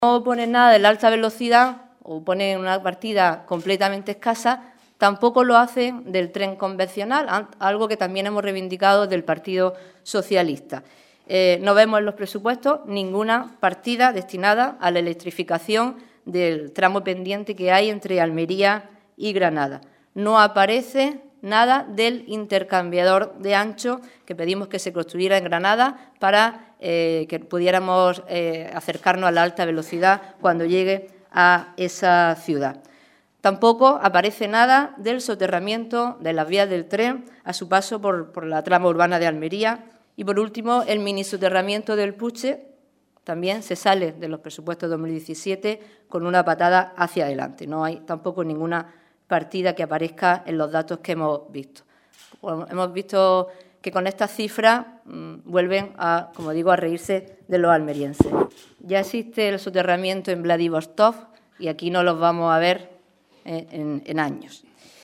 Rueda de prensa que ha ofrecido el PSOE de Almería sobre los Presupuestos Generales del Estado para 2017